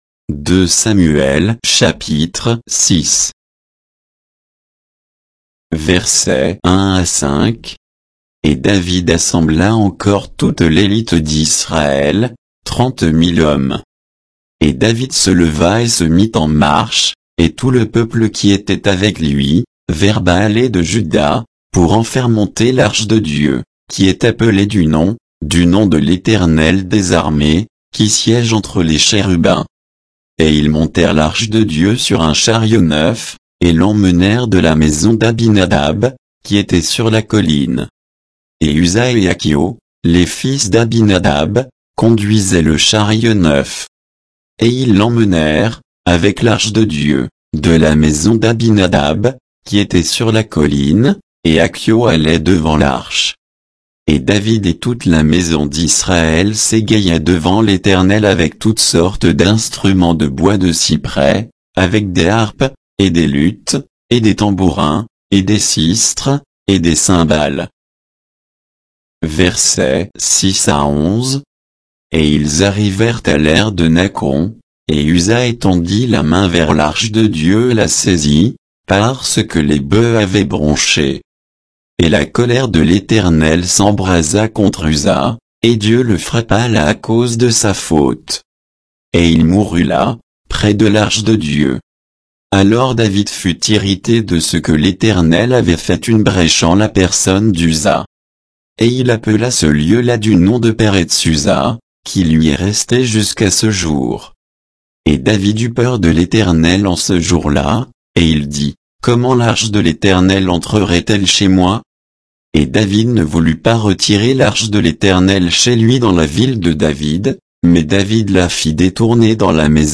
Bible_2_Samuel_6_(sans_notes,_avec_indications_de_versets).mp3